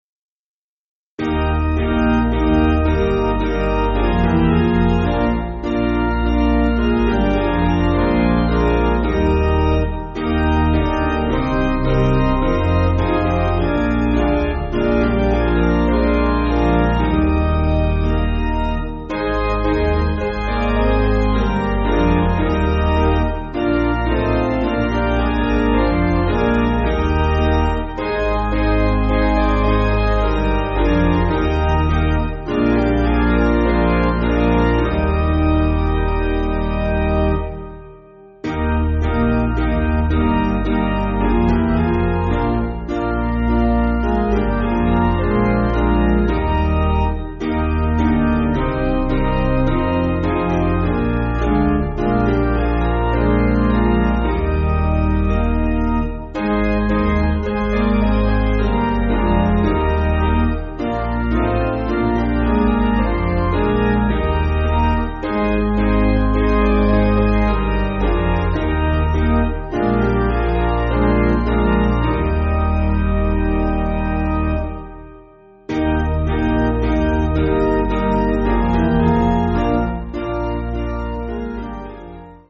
Basic Piano & Organ
(CM)   4/Eb